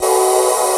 SYNMALE 2.wav